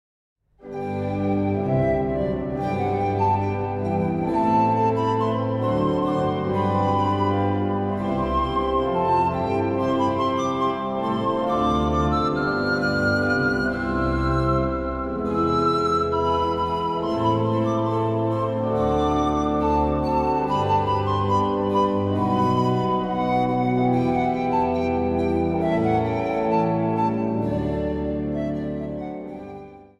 Instrumentale bewerkingen over de Psalmen
Instrumentaal | Hobo
Instrumentaal | Panfluit
Instrumentaal | Synthesizer
Instrumentaal | Viool